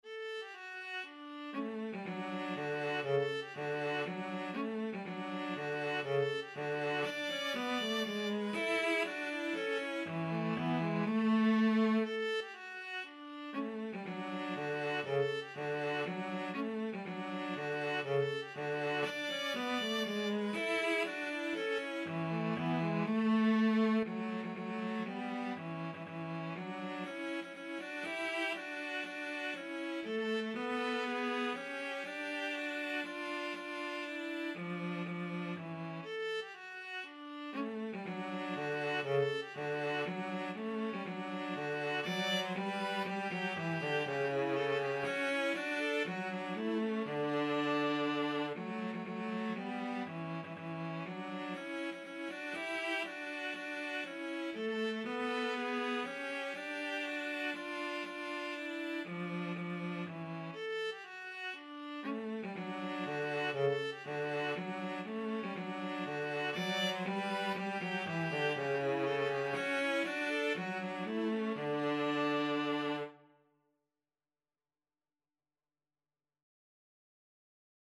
3/4 (View more 3/4 Music)
Allegro (View more music marked Allegro)
Classical (View more Classical Viola-Cello Duet Music)